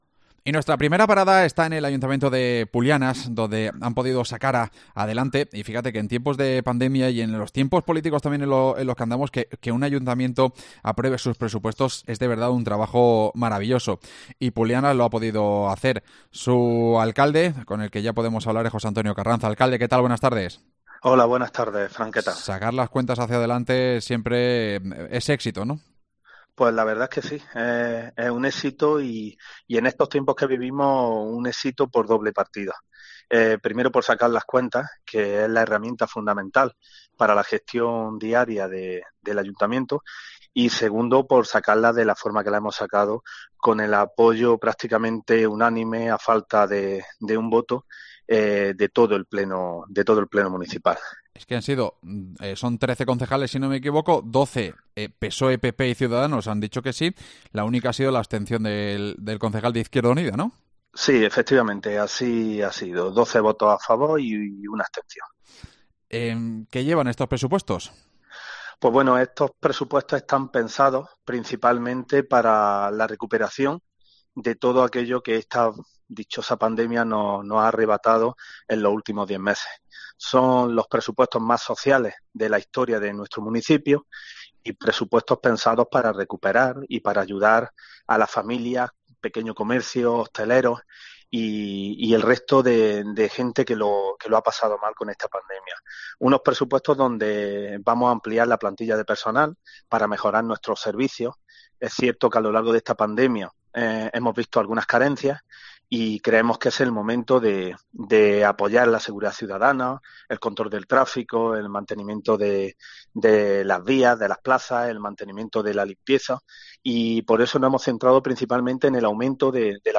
AUDIO: Hablamos con su alcalde, José Antonio Carranza